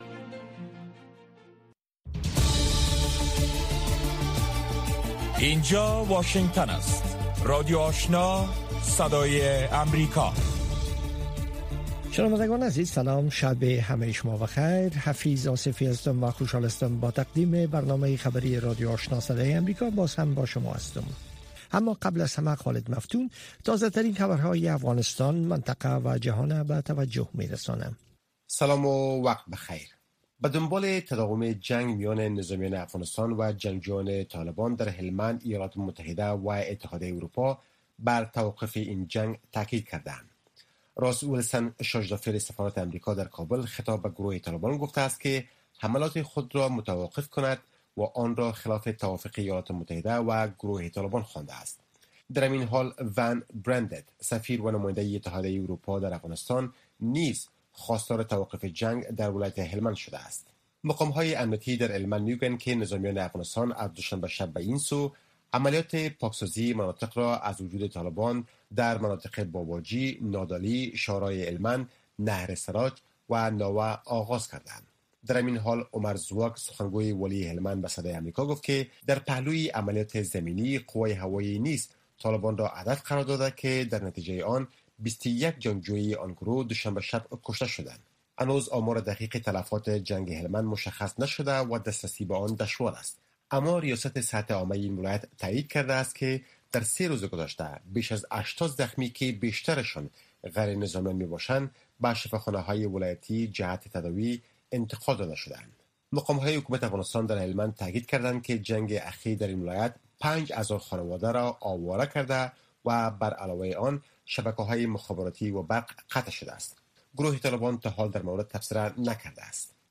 در برنامه خبری شامگاهی، خبرهای تازه و گزارش های دقیق از سرتاسر افغانستان، منطقه و جهان فقط در سی دقیقه پیشکش می شود.